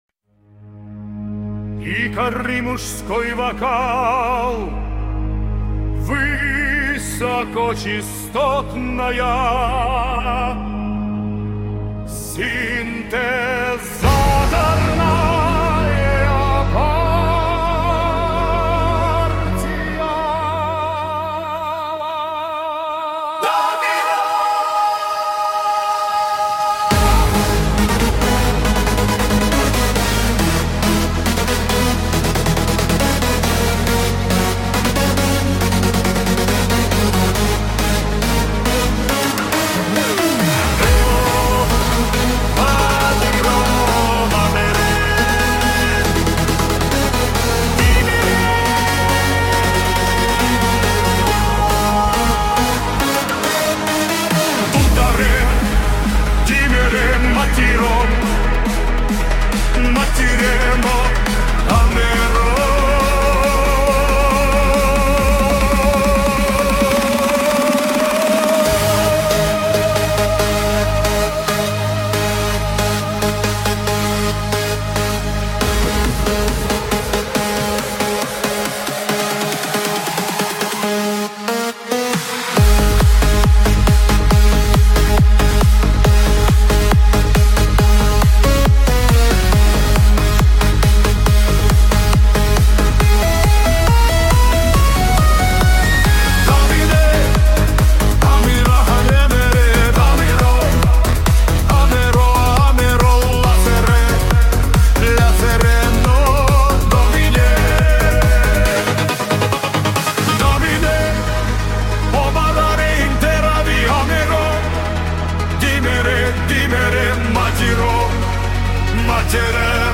Икоры, мужской вокал, высокочастотная синтезаторная партия.